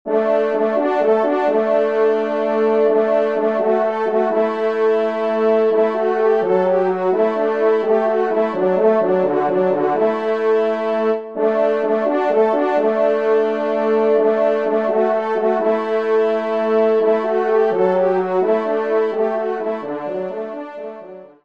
Genre :  Divertissement pour Trompes ou Cors
3ème Trompe